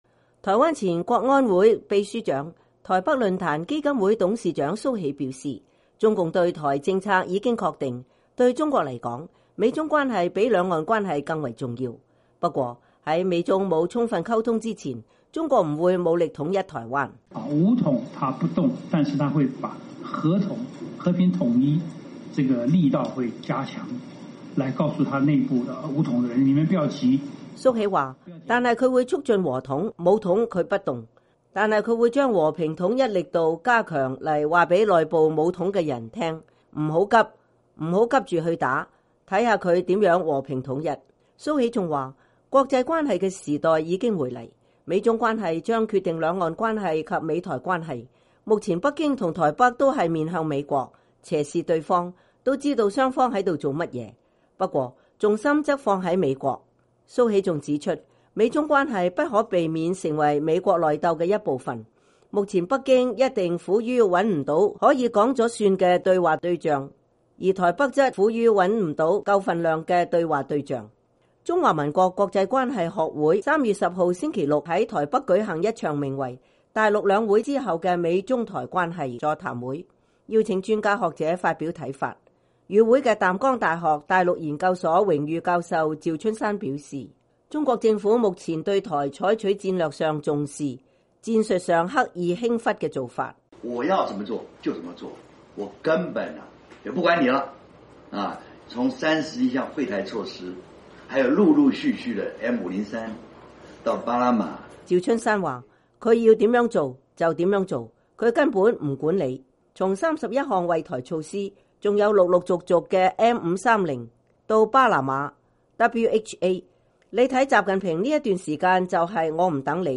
中華民國國際關係學會星期六在台北舉行一場名為“大陸兩會之後的美中台關係”座談會，邀請專家學者發表看法。